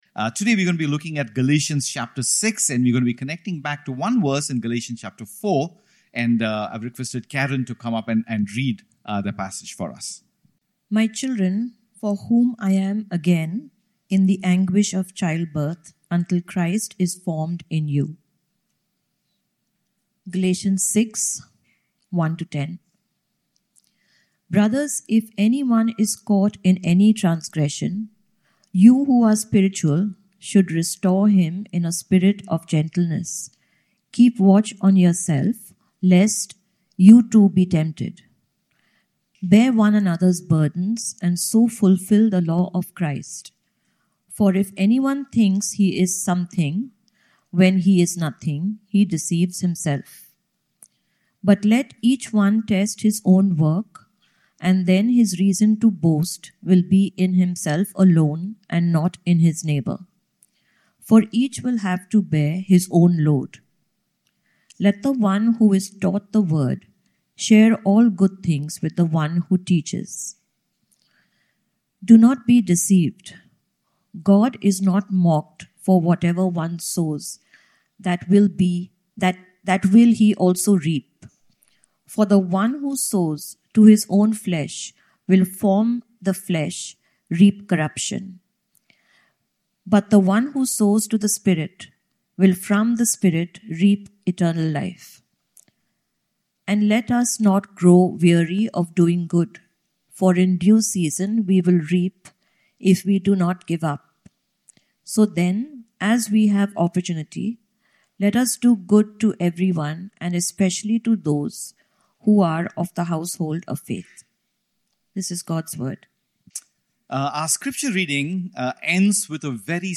Sermons // Gospel-Centered // Life-Changing // Motivating //